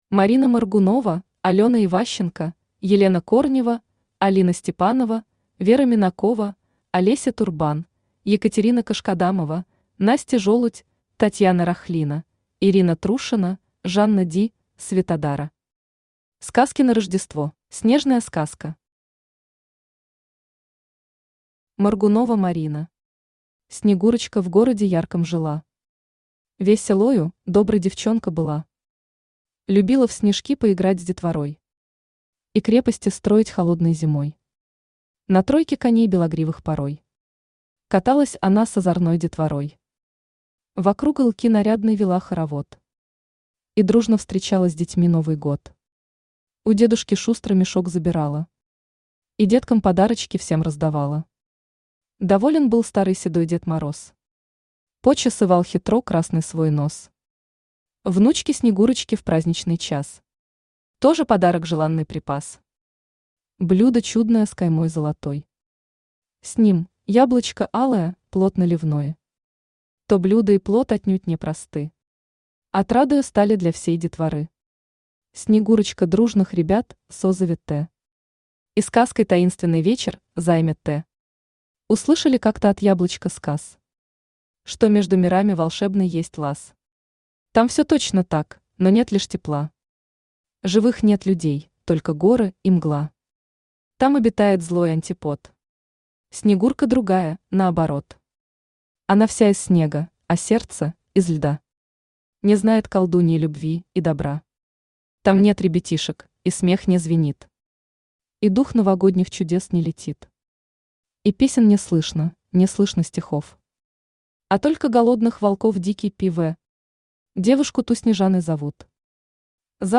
Аудиокнига Сказки на Рождество | Библиотека аудиокниг
Aудиокнига Сказки на Рождество Автор Жанна Ди Читает аудиокнигу Авточтец ЛитРес.